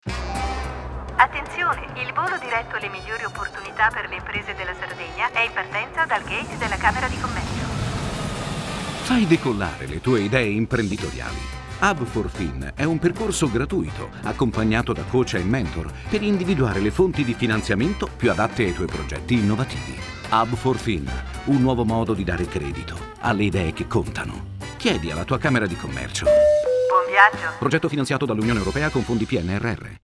Lancio Progetto – spot radio (mp3)
SPOT-RADIO-HUB4FIN-LANCIO-PROGETTO.mp3